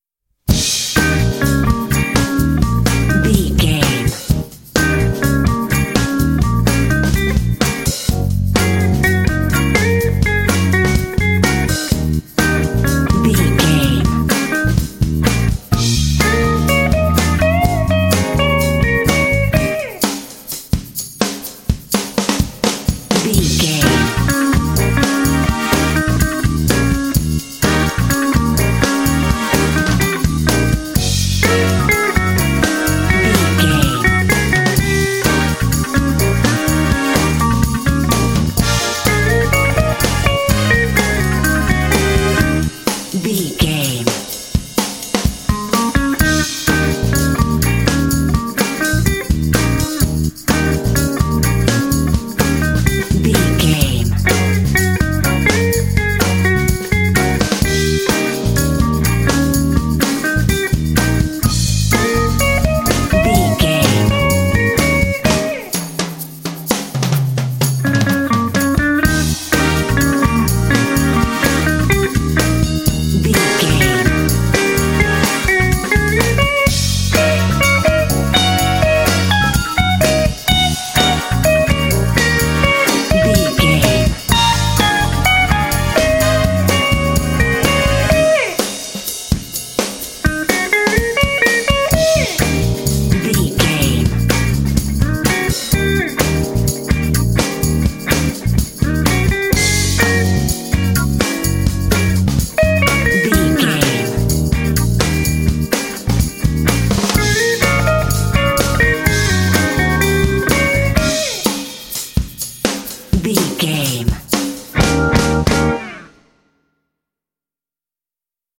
This 12-bar blues track
Aeolian/Minor
E♭
funky
happy
bouncy
groovy
bass guitar
drums
electric guitar
electric organ
brass
electric piano
jazz
blues